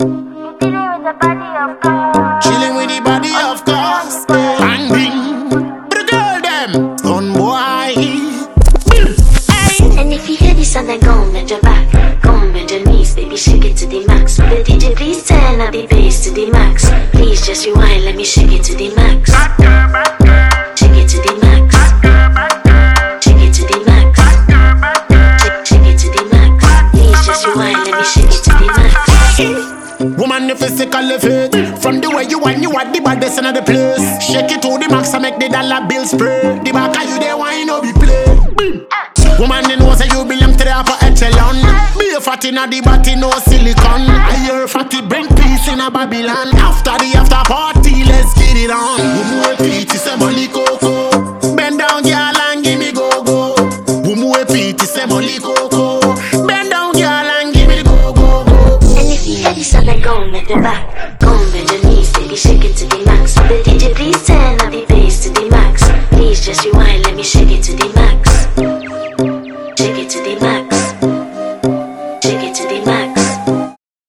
electrifying energy
bold Afro-dancehall energy
Freestyle)Download